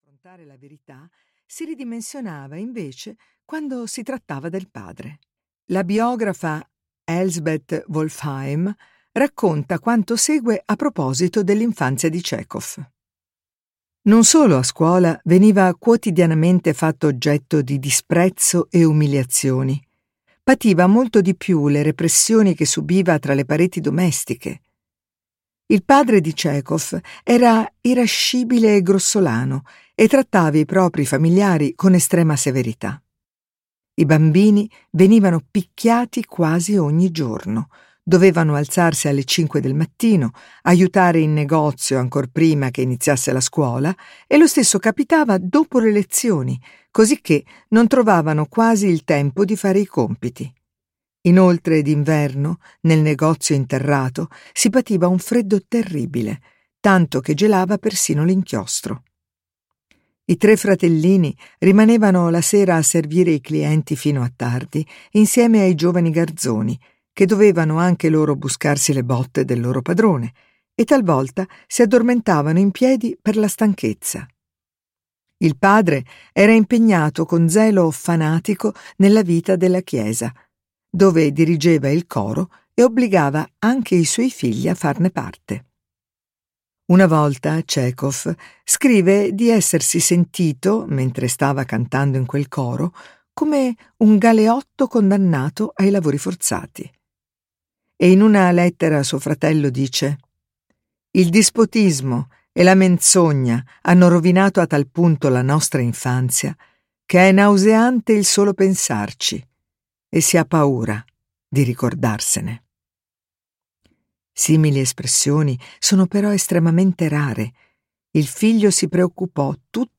"Riprendersi la vita" di Alice Miller - Audiolibro digitale - AUDIOLIBRI LIQUIDI - Il Libraio